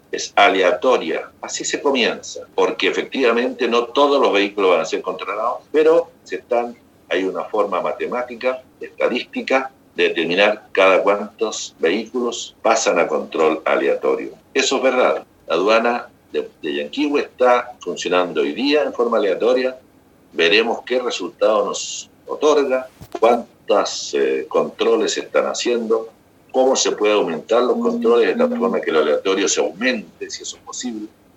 El Intendente de la región de Los Lagos, Harry Jurgensen, explicó que el control a conductores se llevará a cabo de forma aleatoria.